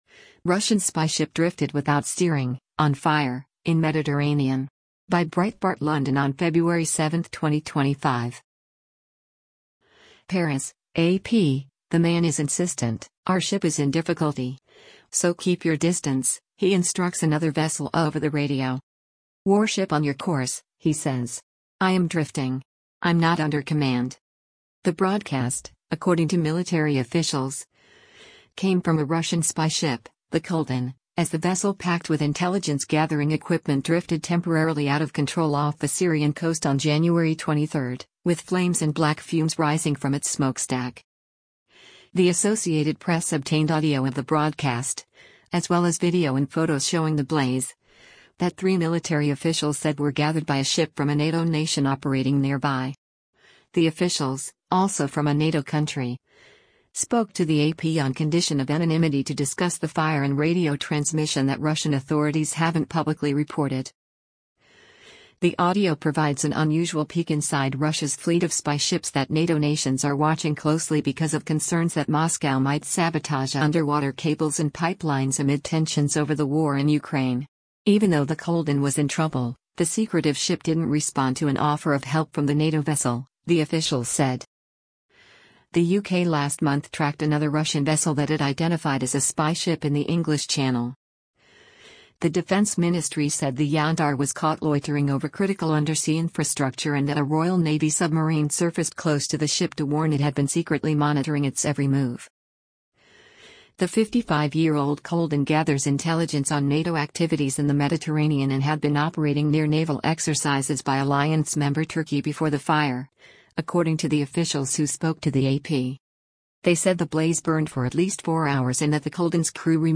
PARIS (AP) – The man is insistent: Our ship is in difficulty, so keep your distance, he instructs another vessel over the radio.
The audio gathered by the NATO ship is a 75-second radio exchange between the Kildin and a Togo-flagged cargo ship, Milla Moon, the officials said.
After the switch, the man with accented English is then heard identifying his vessel as a warship.